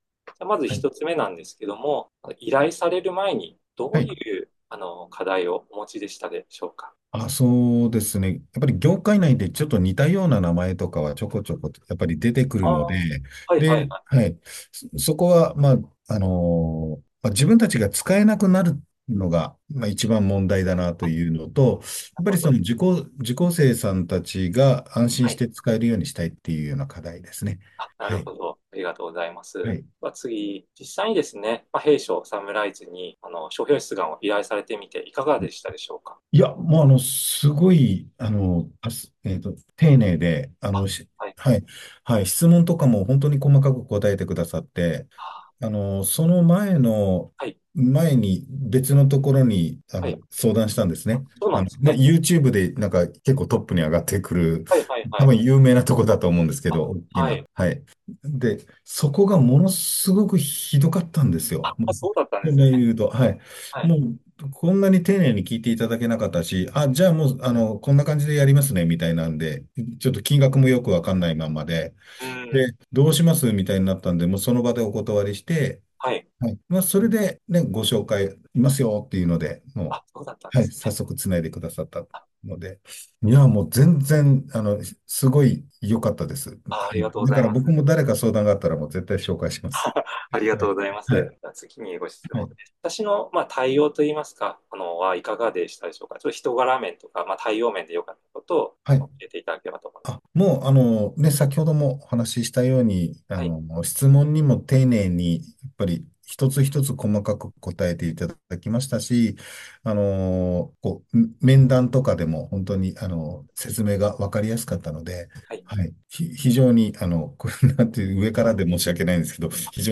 音声インタビュー↓